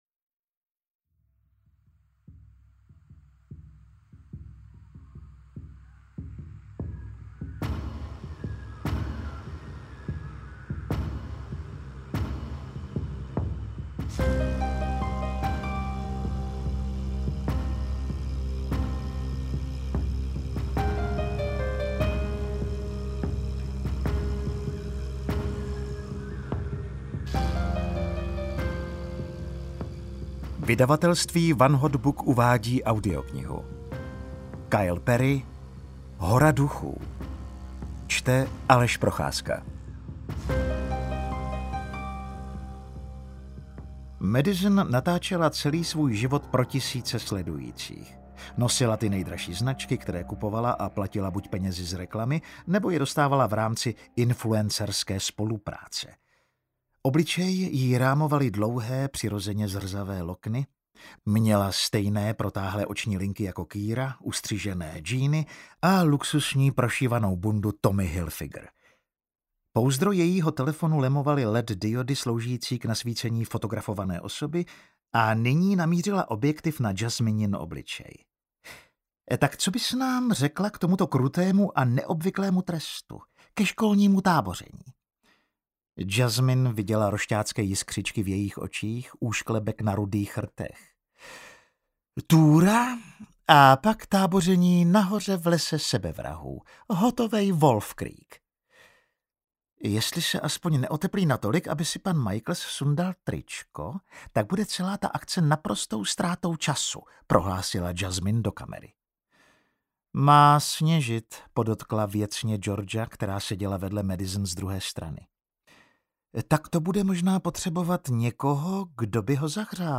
Hora Duchů audiokniha
Ukázka z knihy